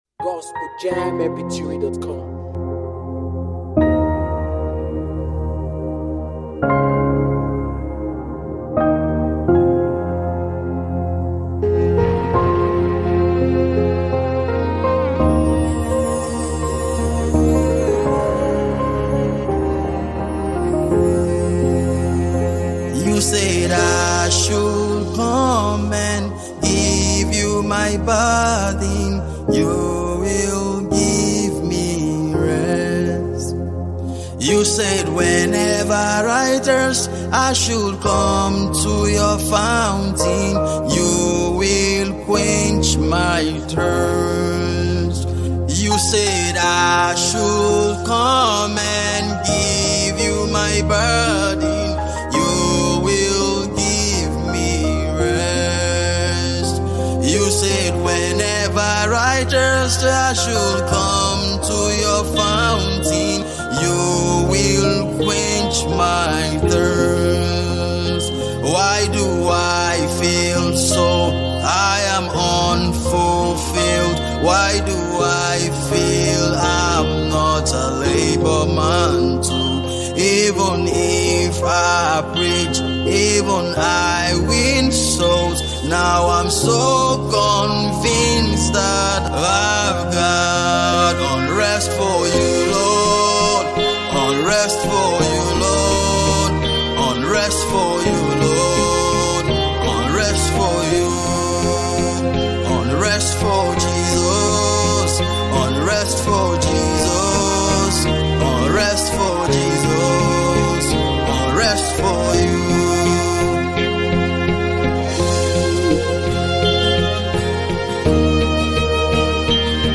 is a passionate sound of revival and total surrender
Worship